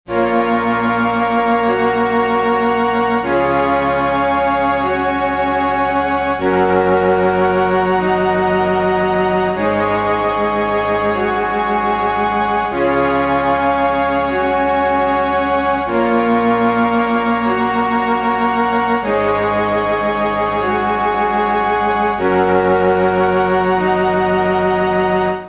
reed pad
Class: Synthesizer